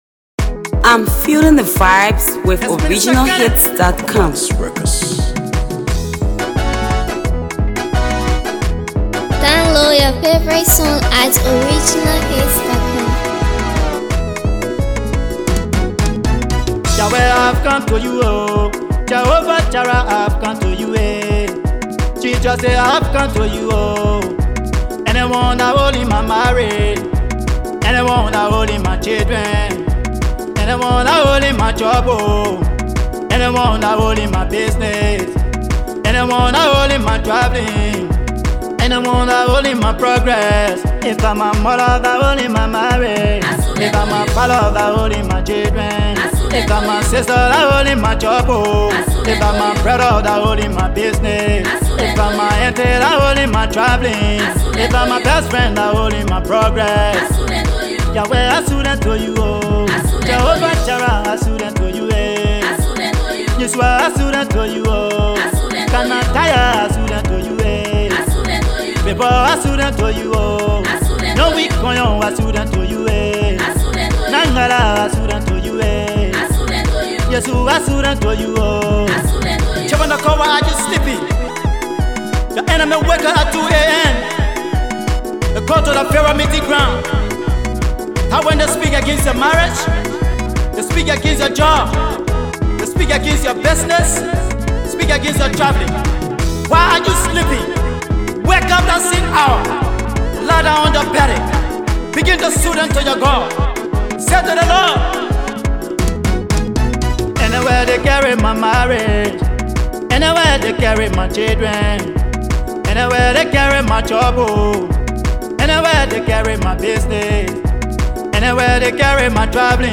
Another gospel song
heartfelt song